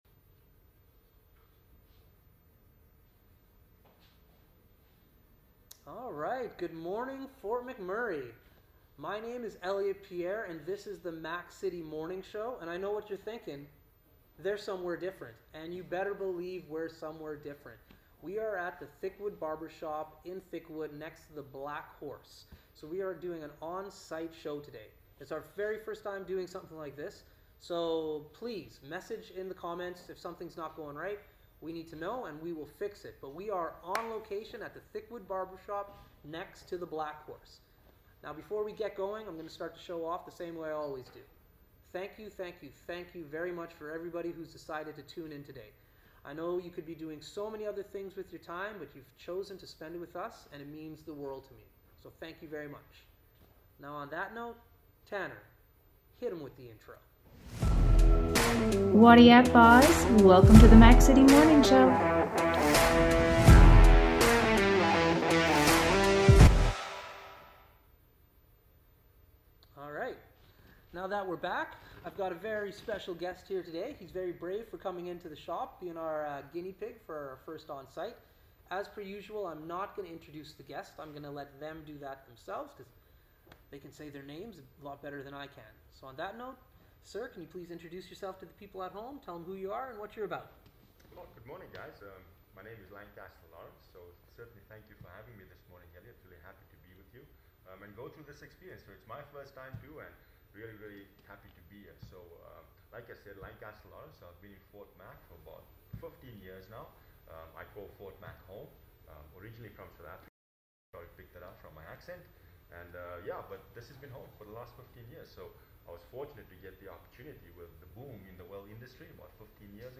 #21: Live at Thickwood Barbershop
Mac-City-Morning-Show-21-Live-at-Thickwood-Barbershop.mp3